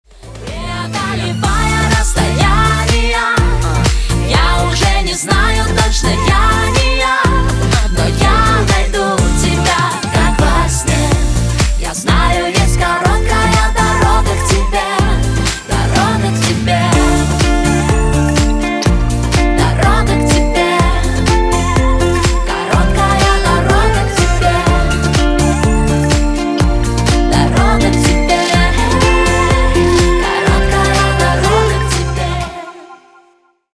поп
женский вокал
русская попса